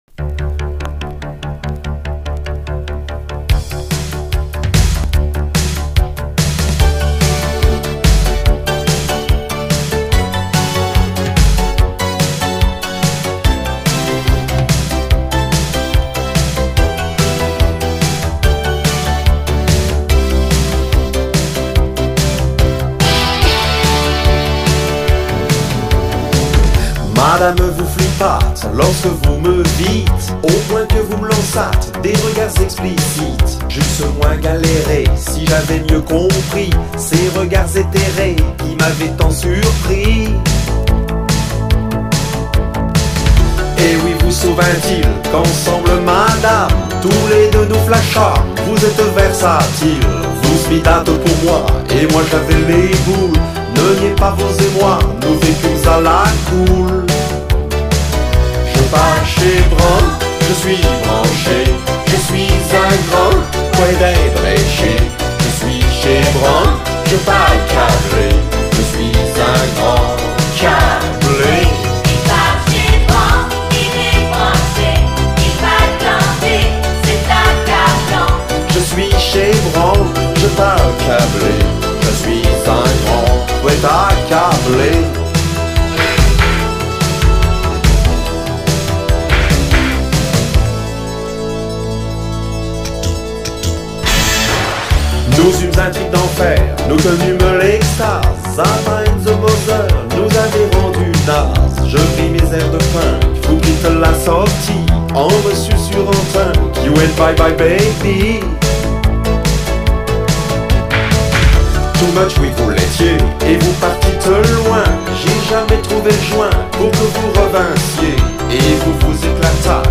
Сделал для себя открытие,узнал что он еще и поет.